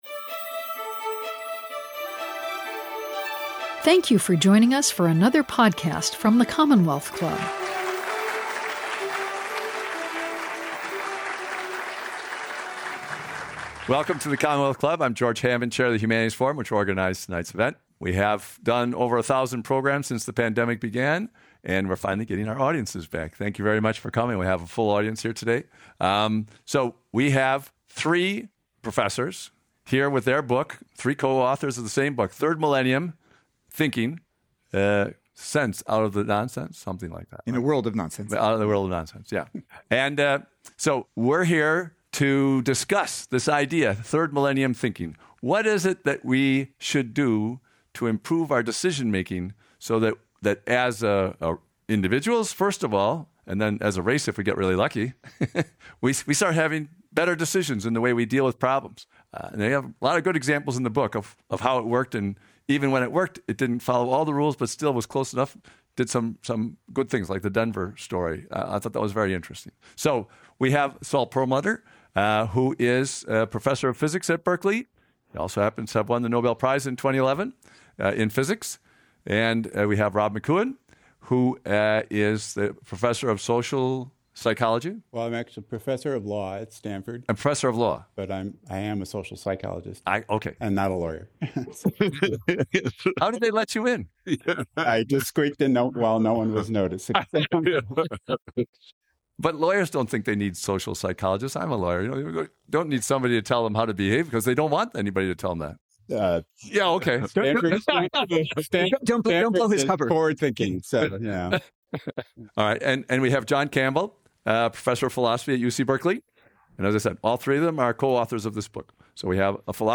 Lecture Audio
Join a Nobel Laureate physicist, a psychologist and a philosopher for a conversation about the tools and frameworks that scientists have developed to keep from fooling themselves, to chart a course through the profusion of possibilities, to better understand the world, and to make intelligent decisions. These trust-building techniques, which the authors call Third Millennium Thinking, can be used to tackle problems both big and small.